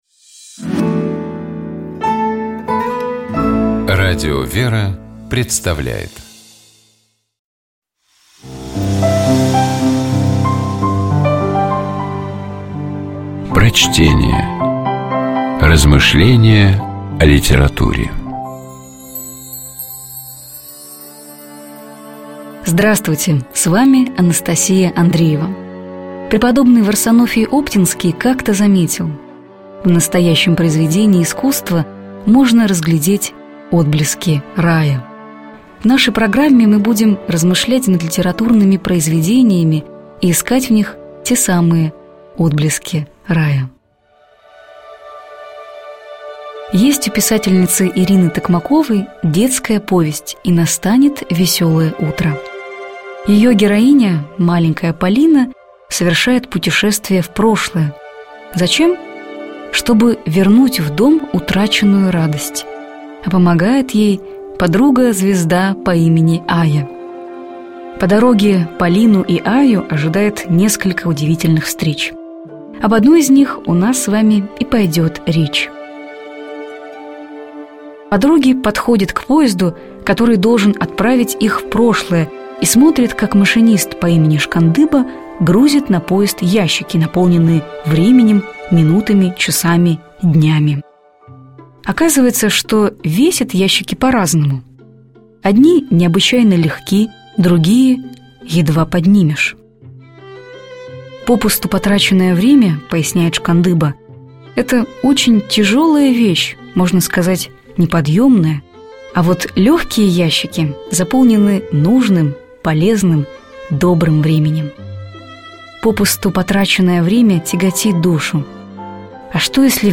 Prochtenie-I_-Tokmakova-I-nastanet-veseloe-utro-O-pustom-vremeni.mp3